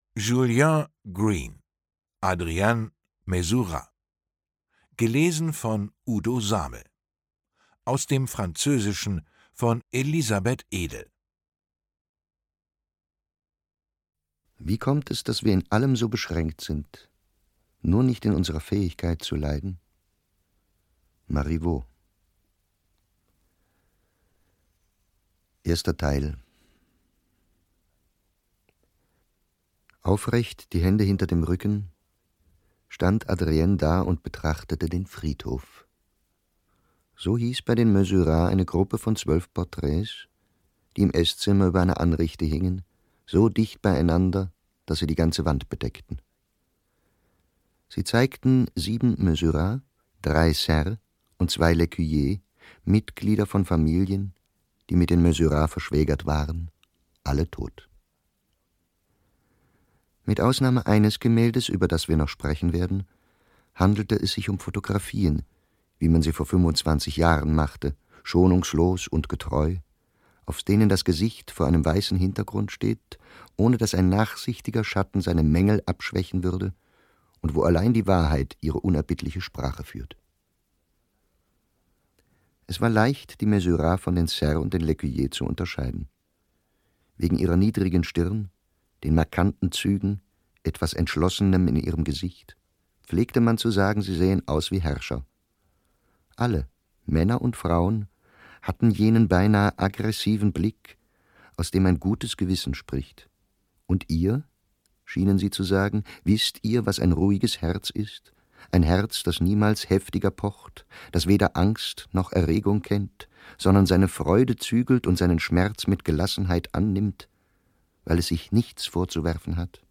Ungekürzte Lesung mit Udo Samel (1 mp3-CD)
Udo Samel (Sprecher)